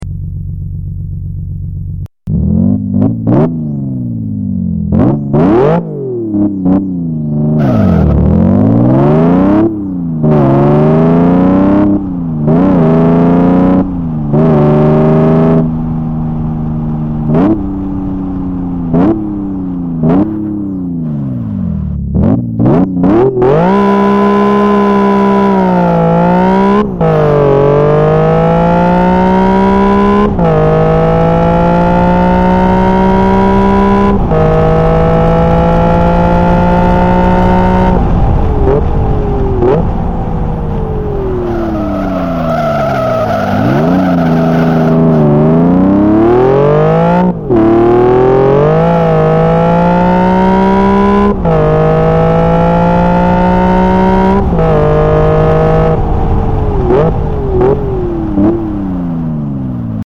In addition I tried to make some sounds based on real vehicles.
Porsche 911.mp3 - 938.4 KB - 1503 views
I listened to the 911 and Gallardo sounds. Actually quite comparable to the real counterparts.